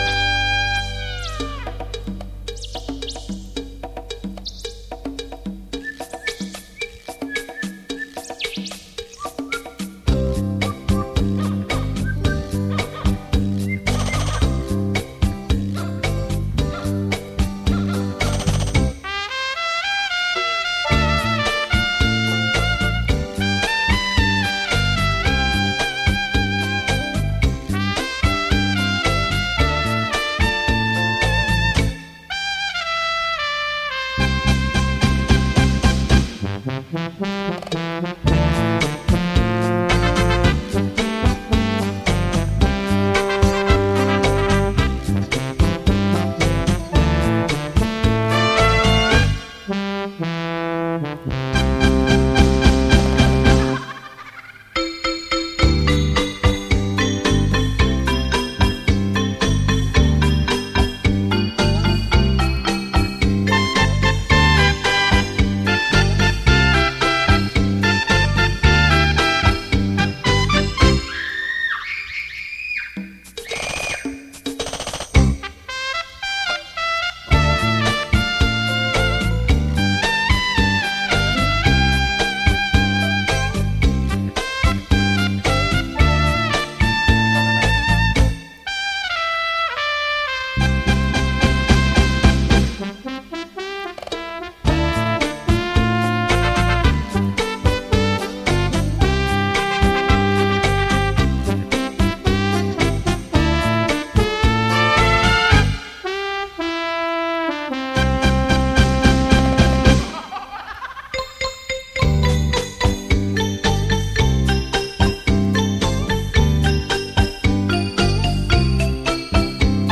磁带数字化
恰恰